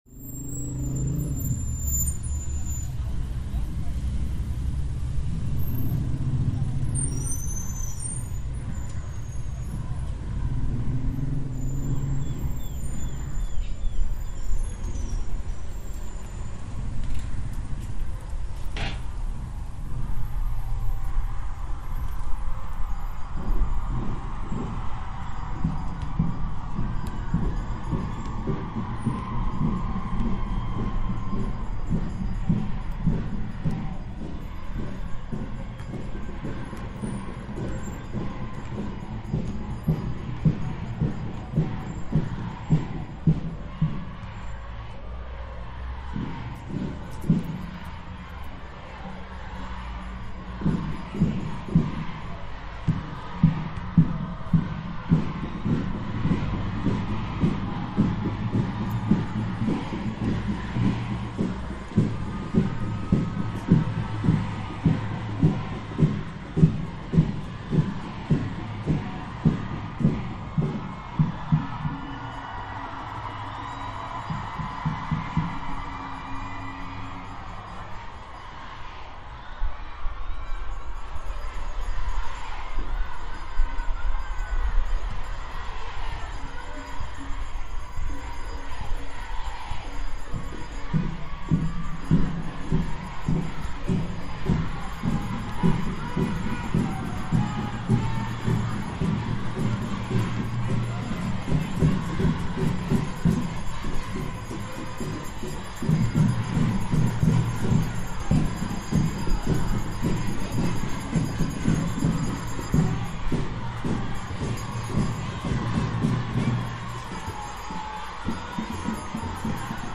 recorridos sonoros peatonales1